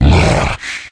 controller_hit_2.ogg